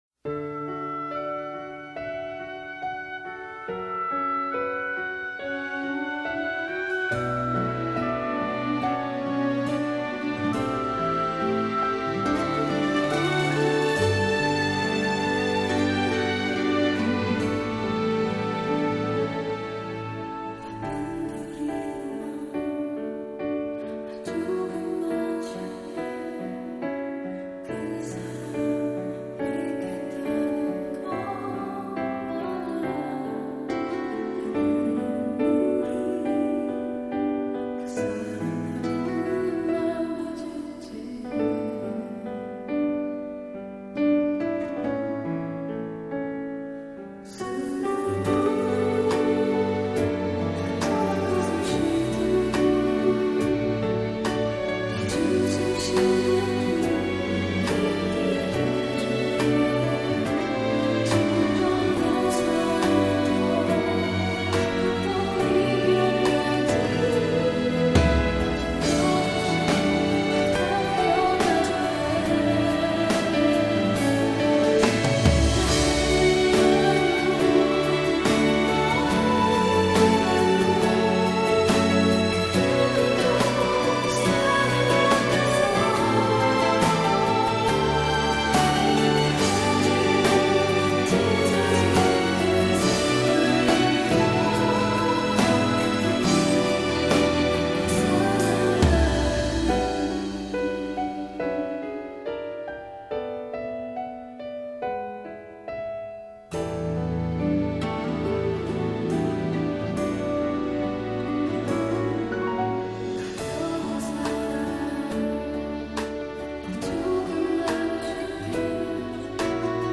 минусовка версия 53330